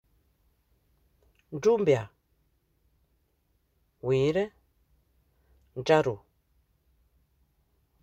Accueil > Prononciation > u > u